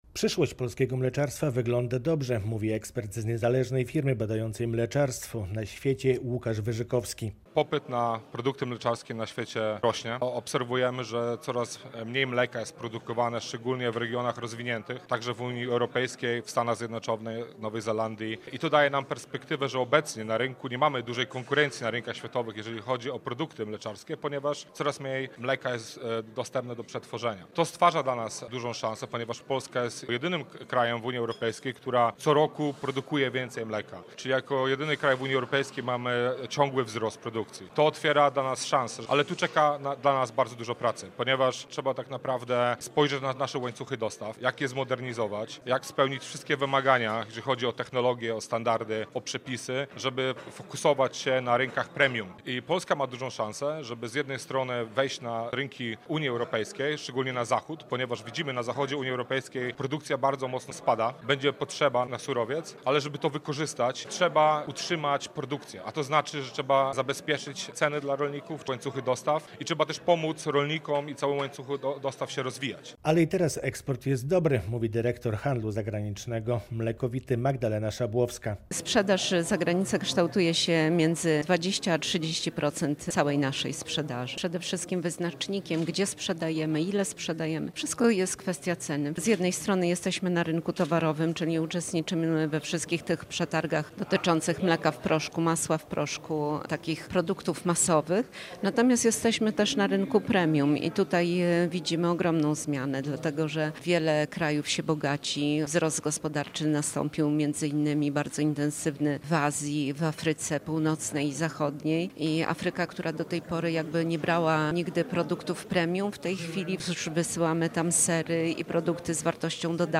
Forum Mleczarskie w Białymstoku - pełna relacja